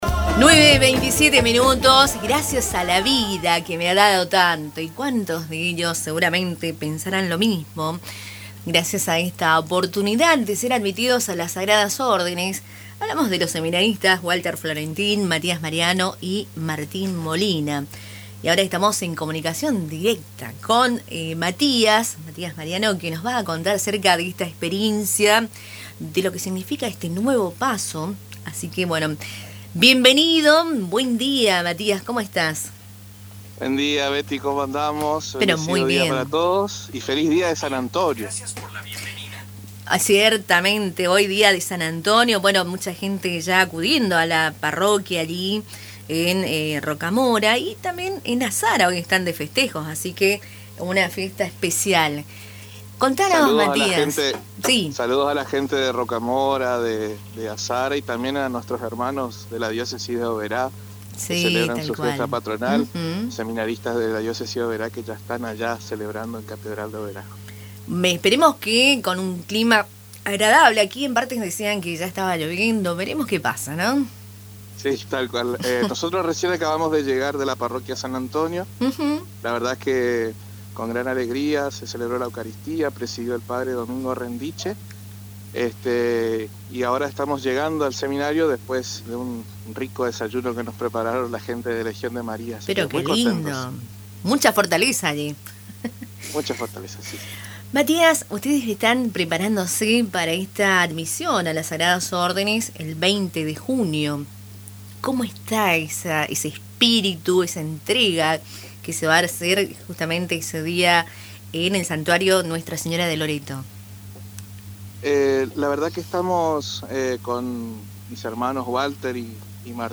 En diálogo con Radio Tupambaé